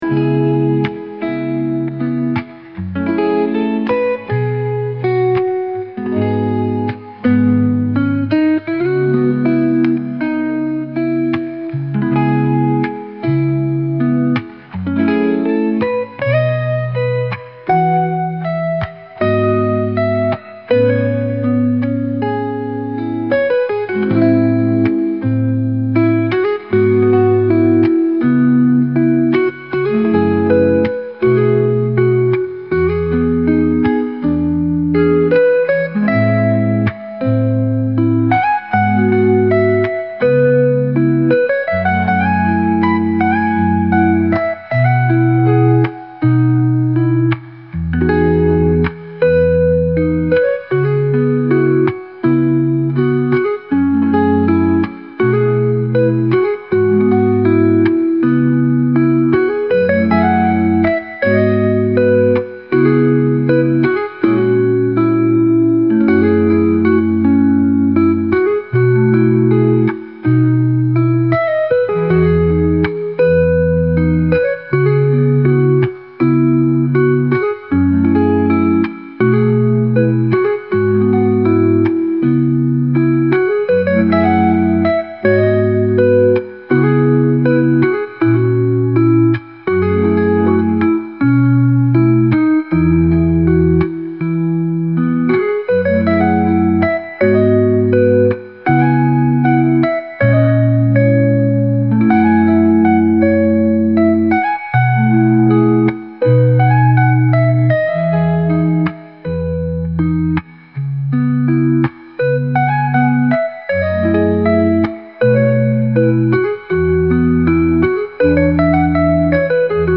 雨音入り癒しのlo-fi/cillミュージック